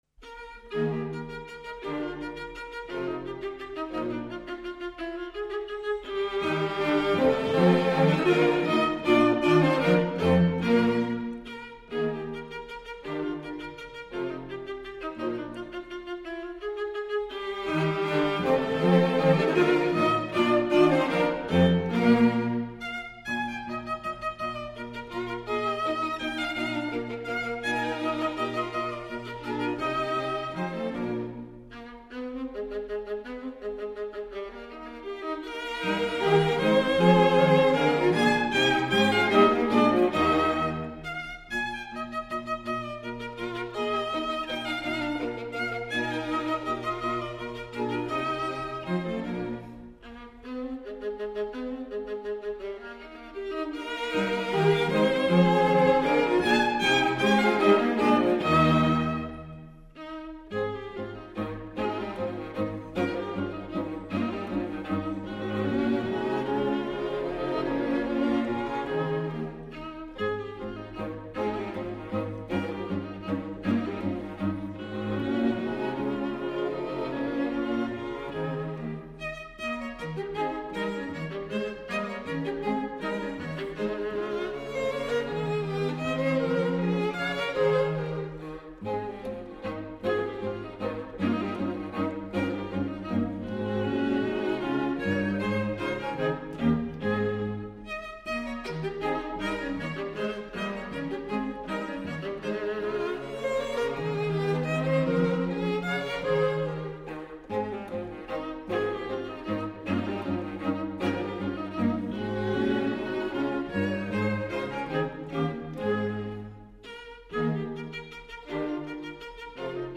String Quartet in E flat major
Menuetto